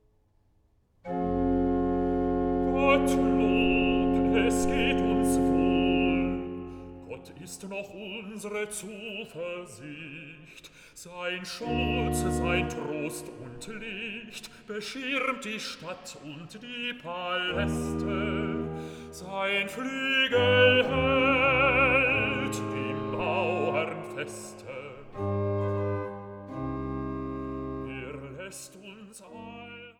Silbermann-Orgel des Freiberger Domes